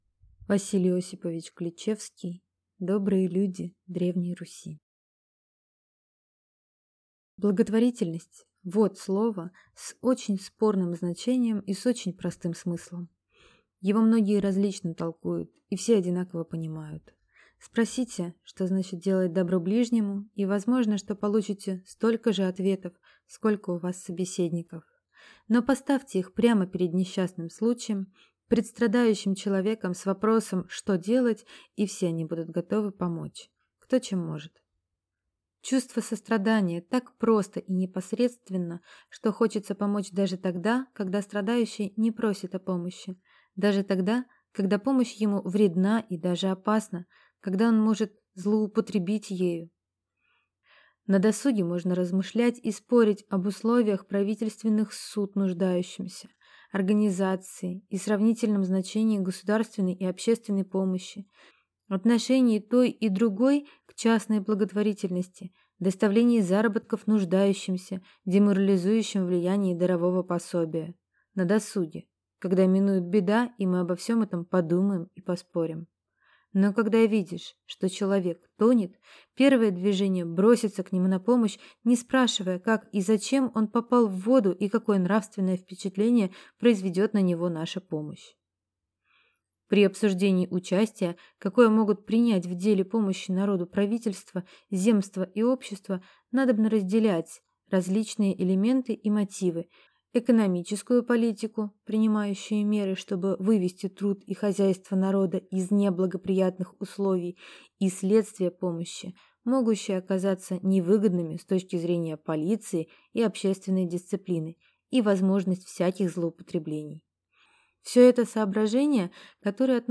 Аудиокнига Добрые люди Древней Руси | Библиотека аудиокниг